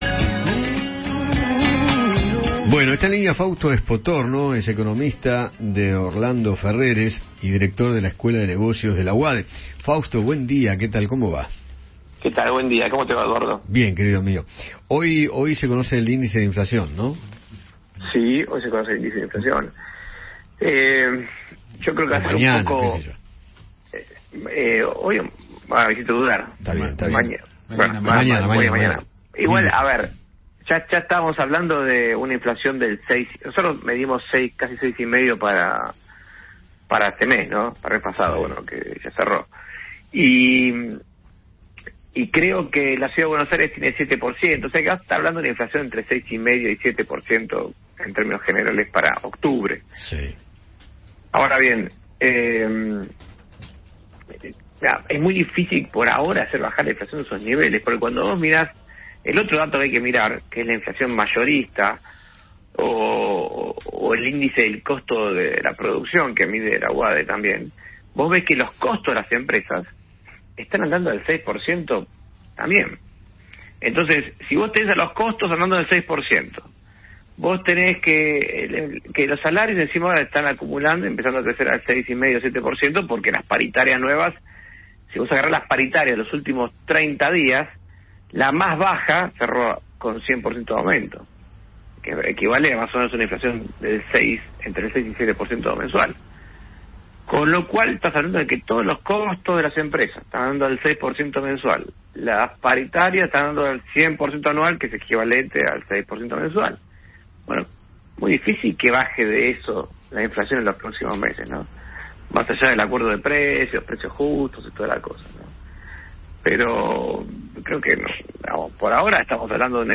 dialogó con Eduardo Feinmann sobre el índice de inflación del mes de octubre que se dará a conocer mañana y analizó la situación económica del país.